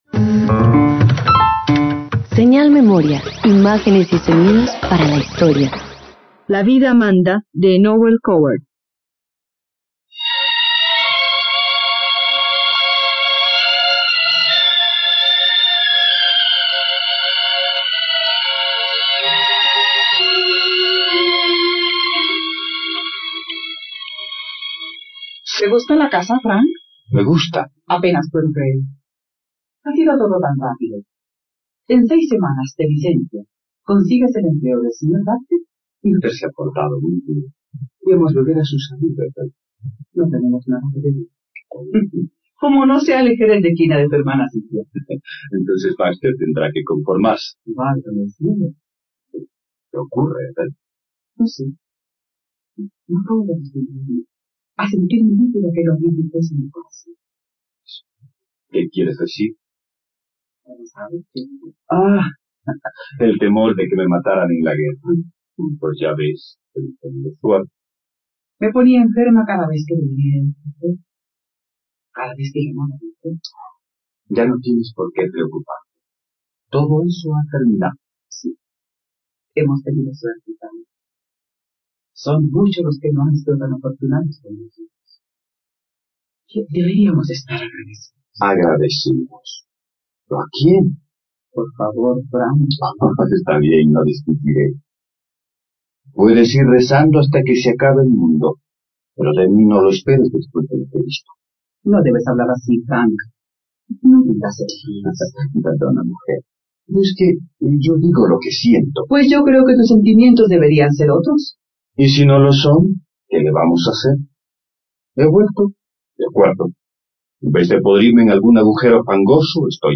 La vida manda - Radioteatro dominical | RTVCPlay